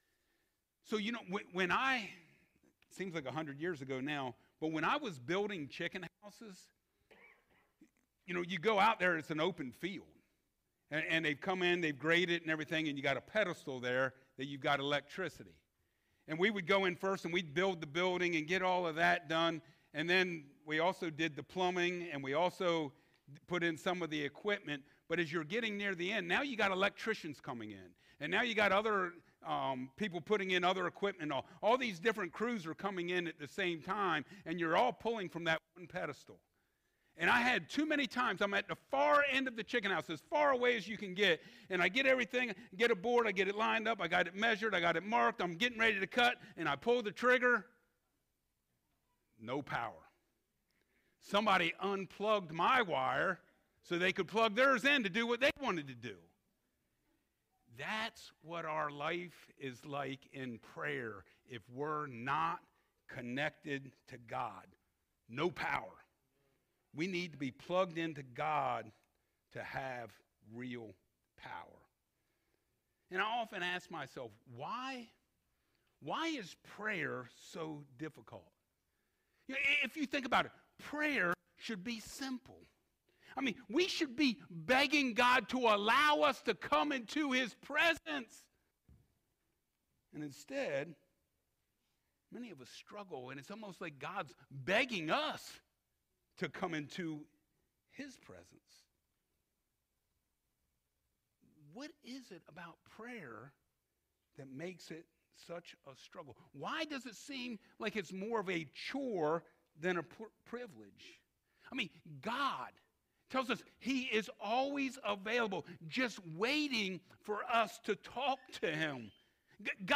Passage: Colossians 4:2-6 Service Type: Sunday Mornings Foundation of Our Faith